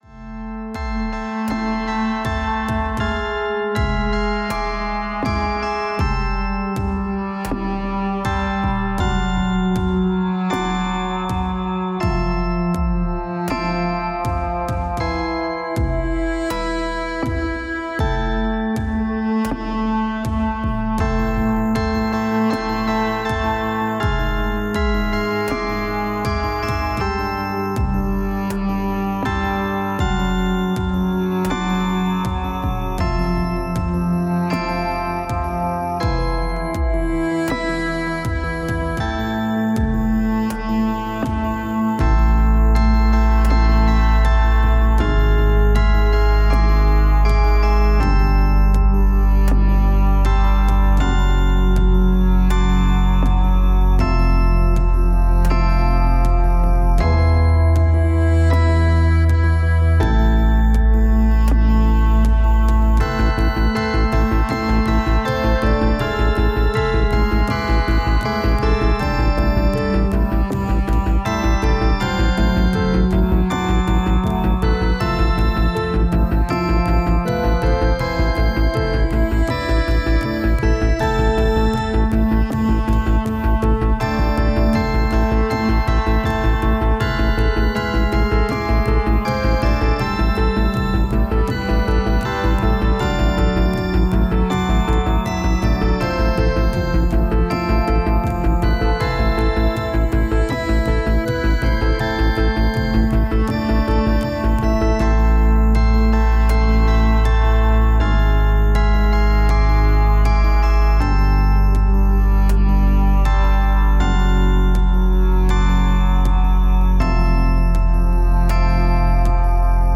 une musique libre de droit épique